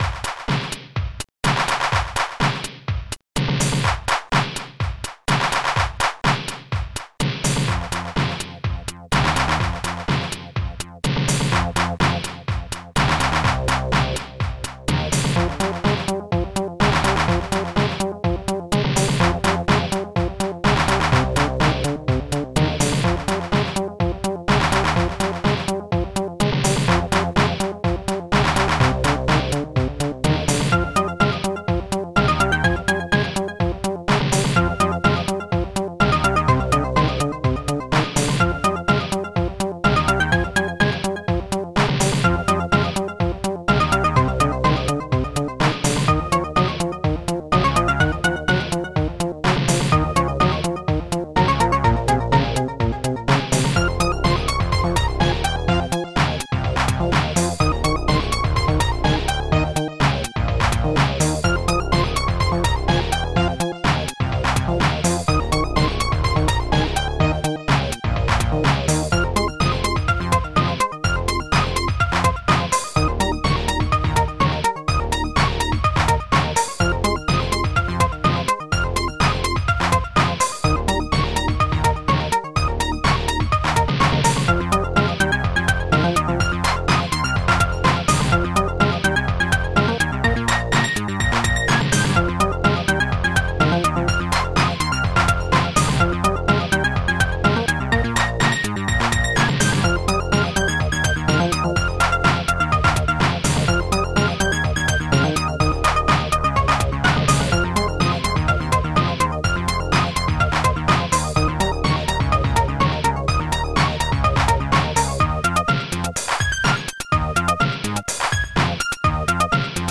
st-04:snare5 st-70:hitbassdrum st-10:clhihat st-10:ophihat st-04:ANIMATE-CLAP st-02:Cymbal1 st-01:WowBass st-70:usualbass st-02:Tubes st-01:Sphere